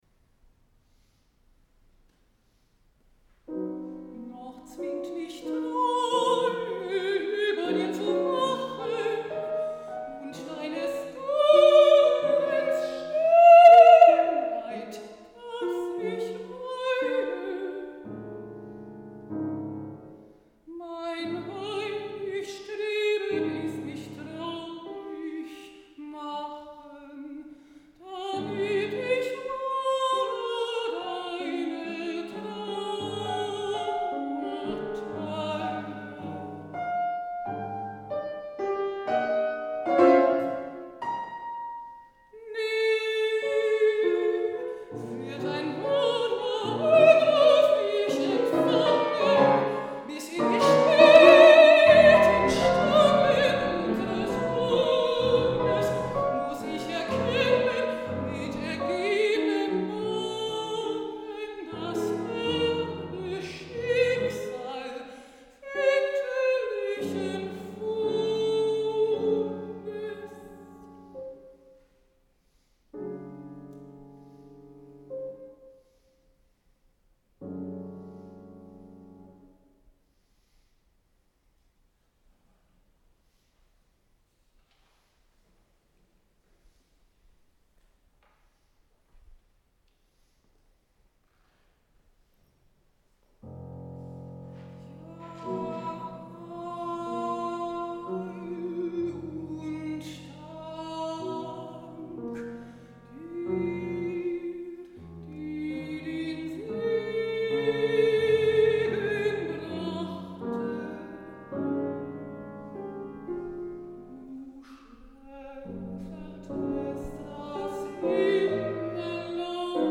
Mitschnitt eines Liederabends
Juli 1994 im Sorbischen Museum Bautzen
Mezzosopran
Klavier